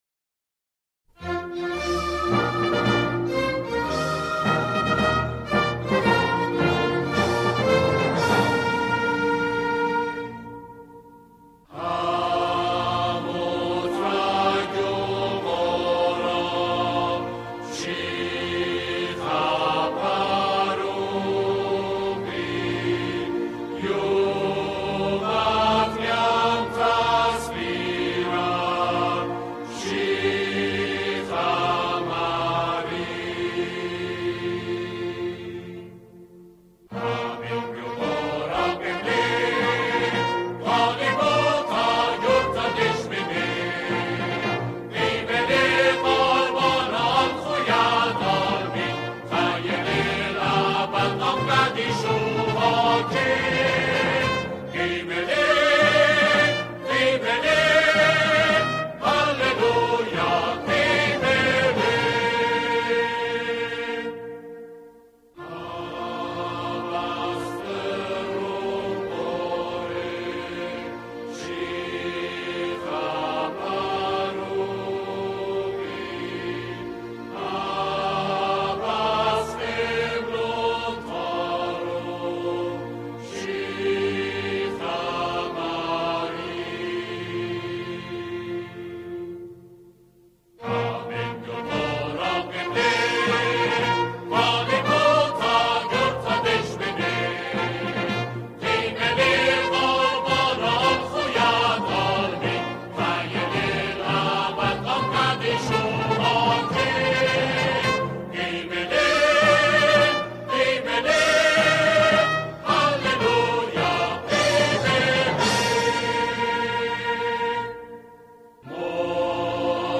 همخوانان این اثر، گروهی از جمعخوانان هستند.